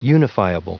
Prononciation du mot unifiable en anglais (fichier audio)
Prononciation du mot : unifiable